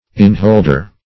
Innholder \Inn"hold`er\